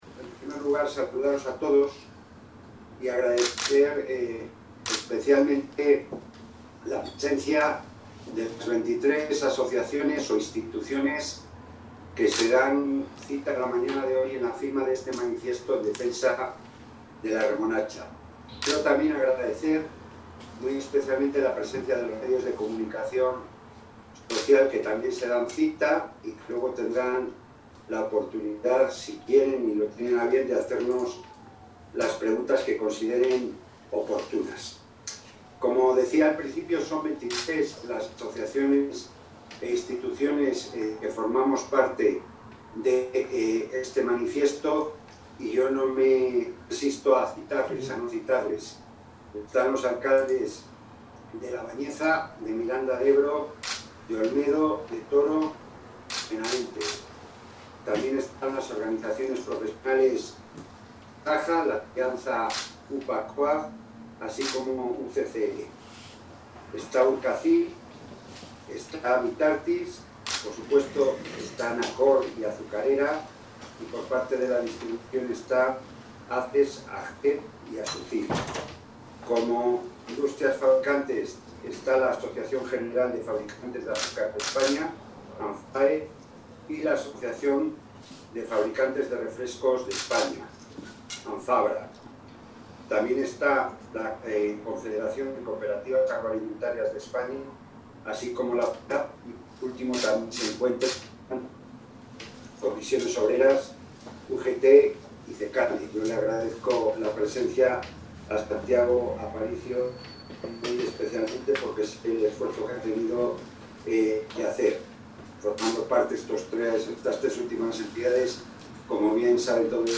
Intervención del consejero de Agricultura, Ganadería y Desarrollo Rural.